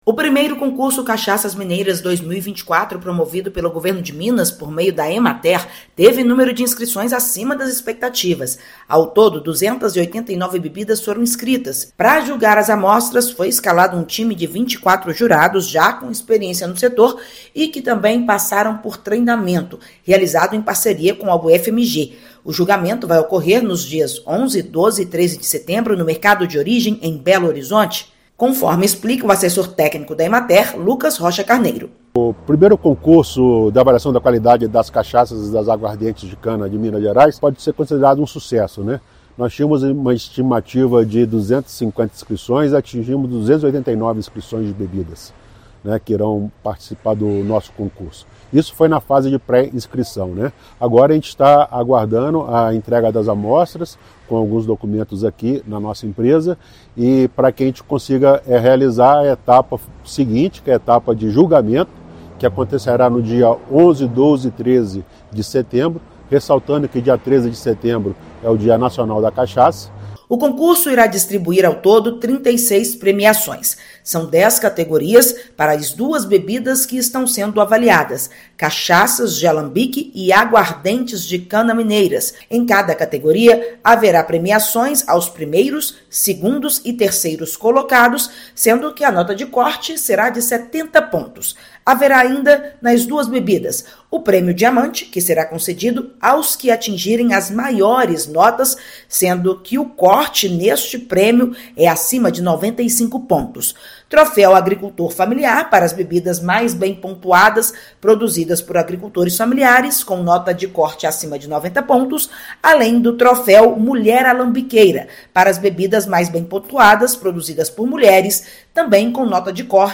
Competição inédita é realizada pela Emater. A etapa de julgamento das amostras será realizada de 11 a 13/9. Ouça matéria de rádio.